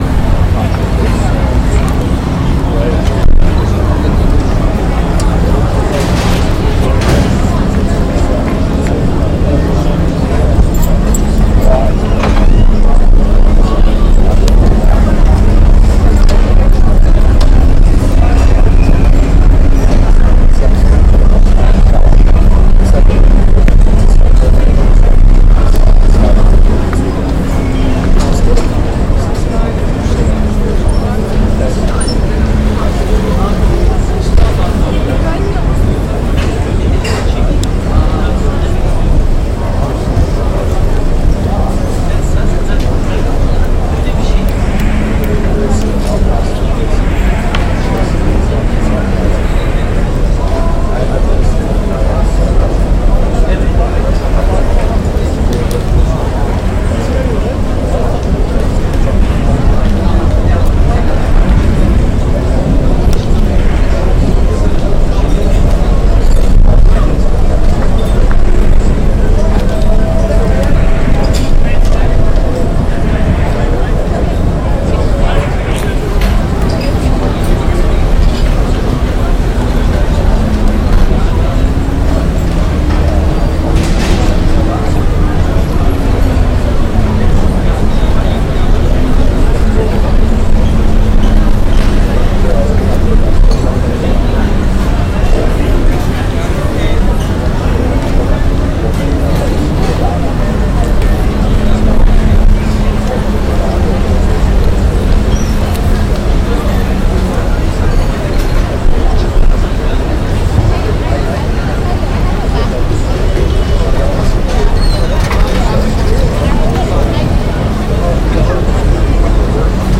Based on an audio diary in which all sounds are recorded continuously over the course of four-days, the work takes shape as an amplification procedure: a room fitted with loudspeakers that play back the daily accumulation of audio. Including the input from a diversity of participants who record everything they do and everyone they meet, the work performs as an auditory composite of life lived. ( installation recording )
dirtyearforum_istanbul.mp3